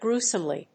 音節grue･some･ly発音記号・読み方grúːs(ə)mli